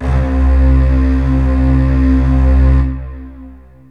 STRINGS 0002.wav